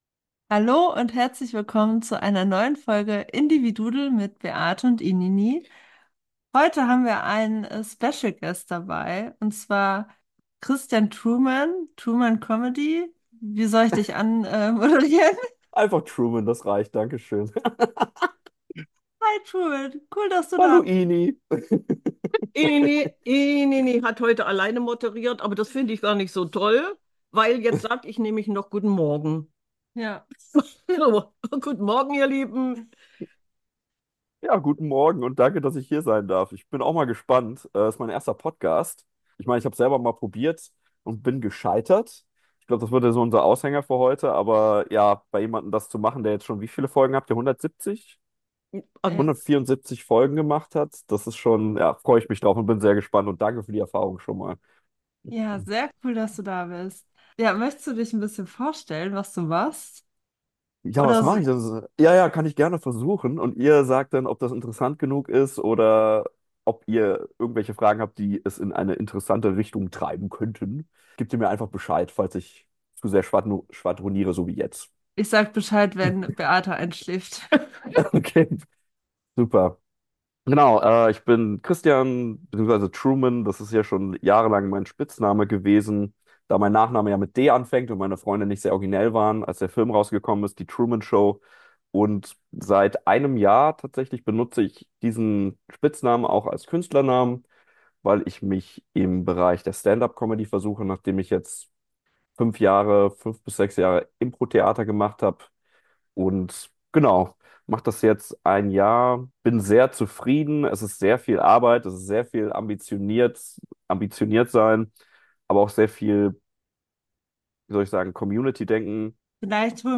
Ein gescheitertes Interview